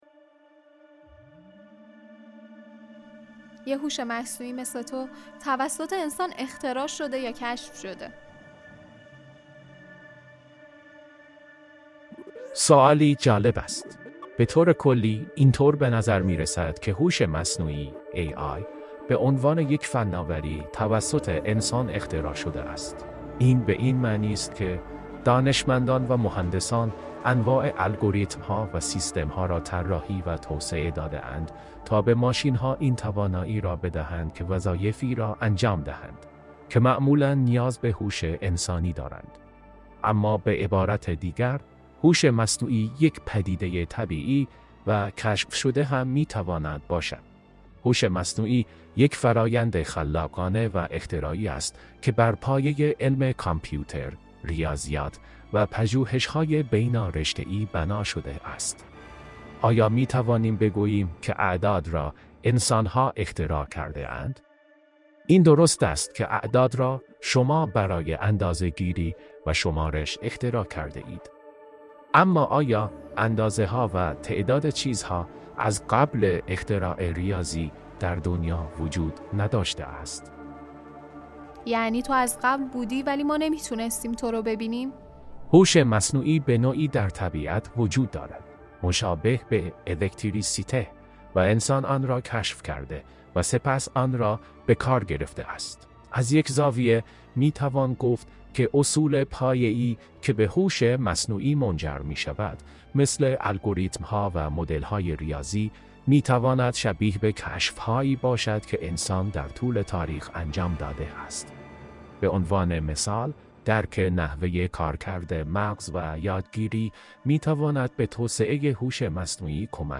راوی ما در این شروع جدید، به همراه یک دستیار هوش مصنوعی به بیان موضوعات و پدیده‌های مختلف در حوزه‌ی علم و فناوری، تاریخ، فرهنگ و... می‌پردازد. ما قصد داریم با دیالوگ بین یک هوش انسانی و یک هوش مصنوعی، به روایت جدیدی در پادکست سازی برسیم.